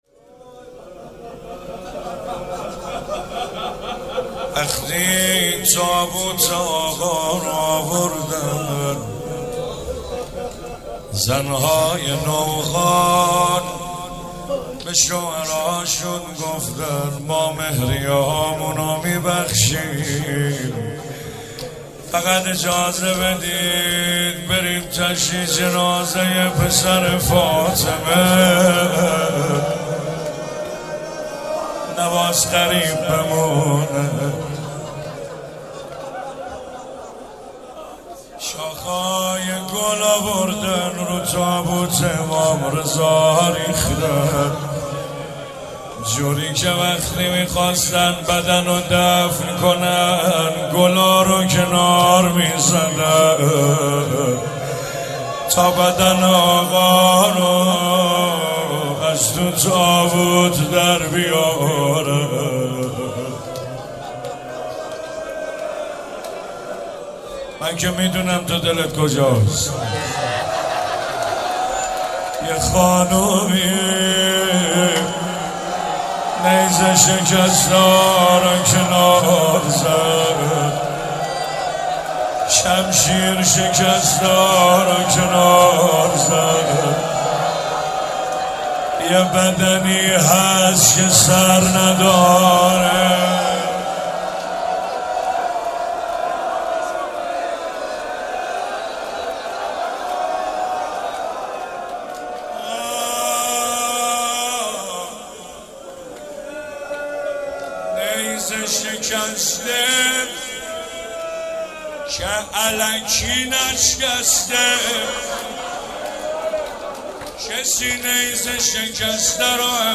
روضه امام رضا